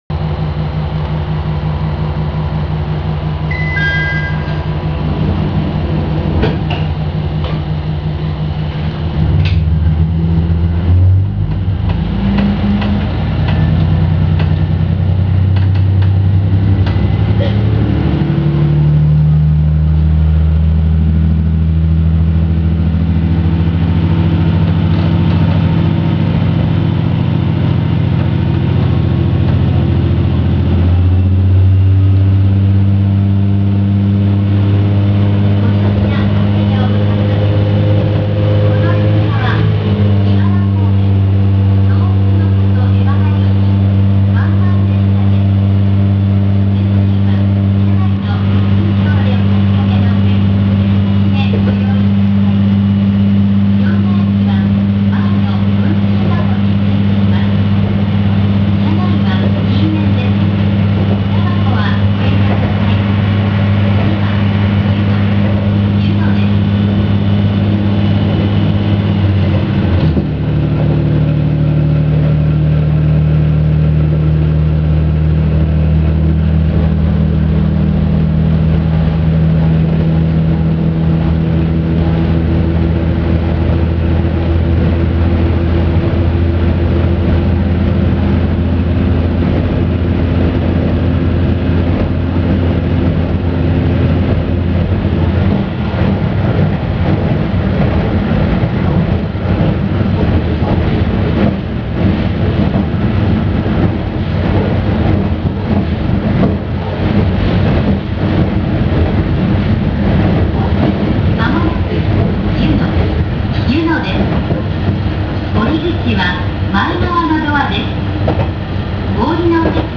・IRT355形走行音
【井原線】総社〜湯野（2分52秒：937KB）
ドアチャイムは新型ディーゼルカーにありがちな音。走行音はなんだかずいぶんと賑やかなような…。
自動放送は、聞き間違いでなければ地方のＪＲや私鉄の駅放送で良く聞ける女性の放送と同じ声。